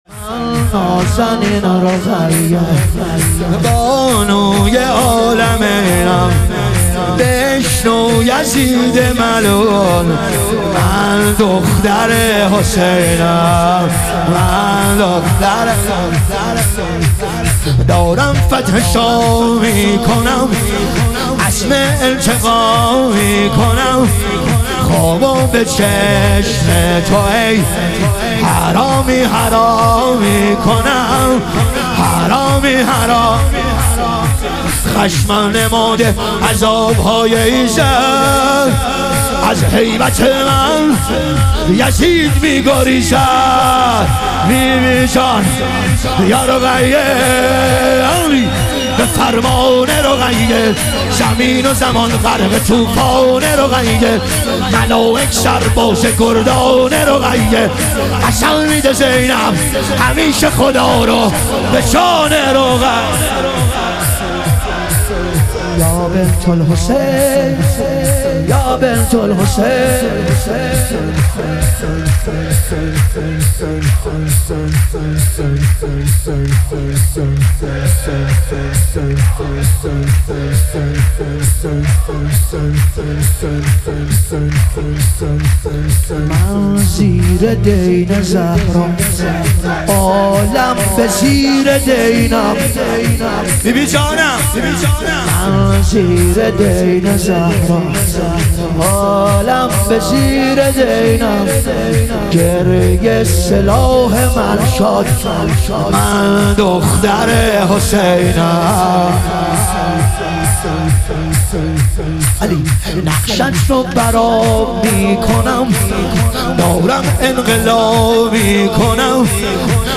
شور
شب ظهور وجود مقدس حضرت رقیه علیها سلام